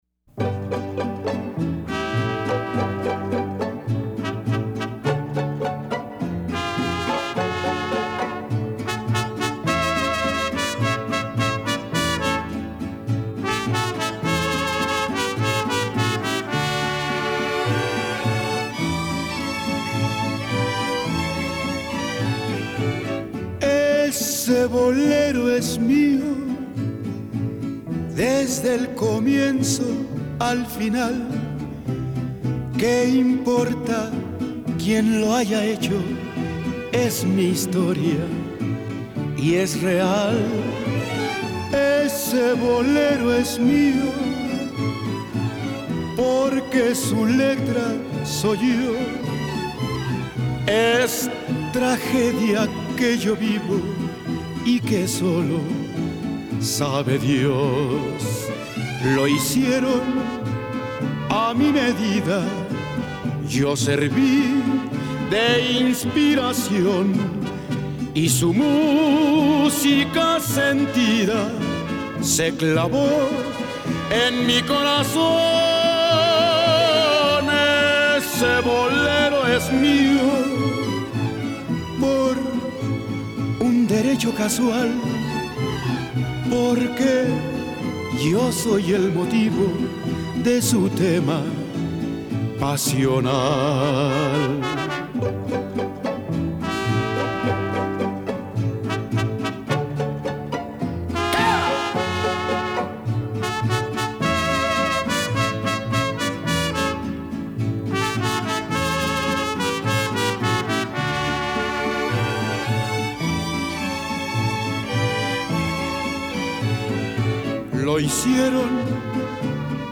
Inspiración y pasión total.